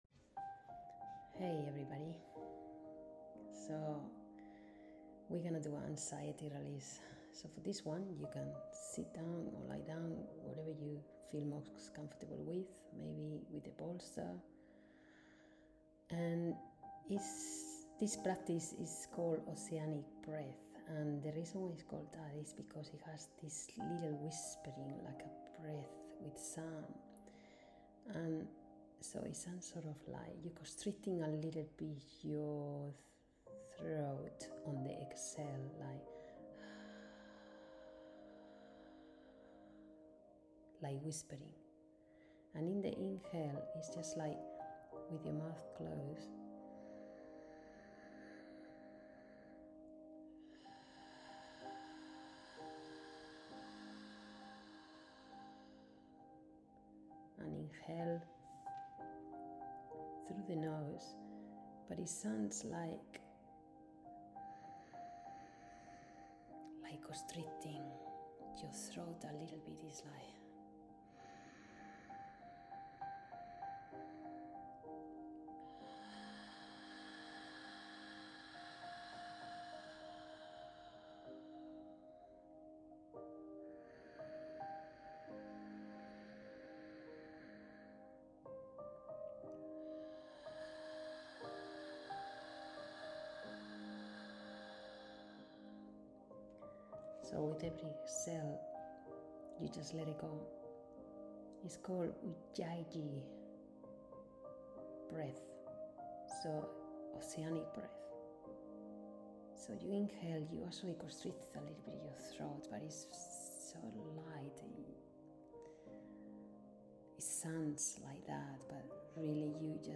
INSTRUCTIONS: Inhale deeply, Slightly constrict throat on exhale (like a "ha" whispering), Breathe with sound.
Anxiety Release Guided BreathworkMP3 • 7586KB